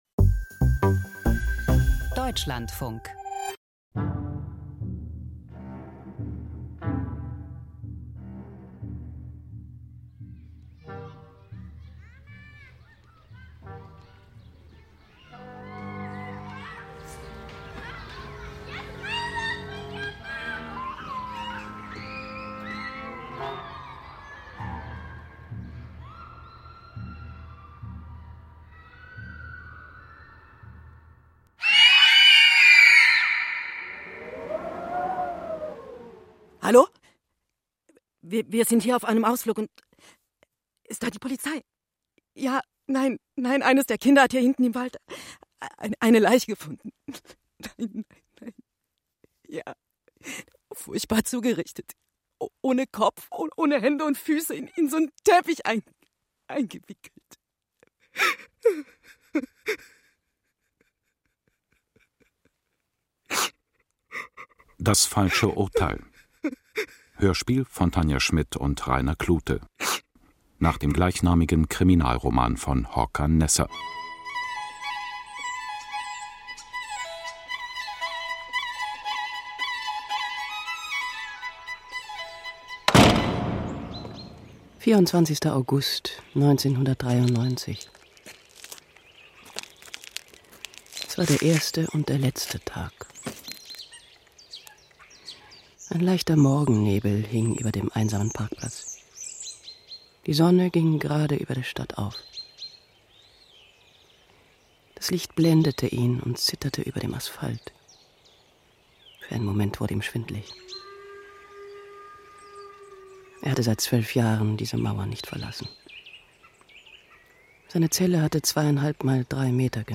Hörspiel: „Souterrain – Soterrani“ von J. M. Benet i Jornet